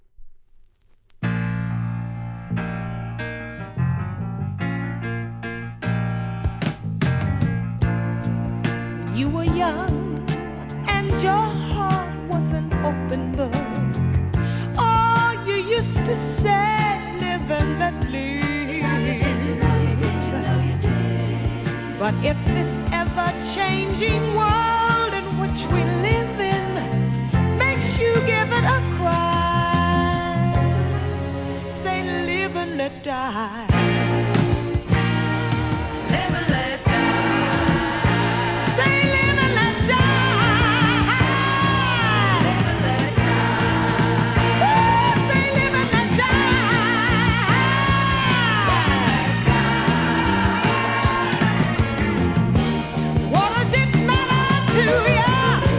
¥ 330 税込 関連カテゴリ SOUL/FUNK/etc...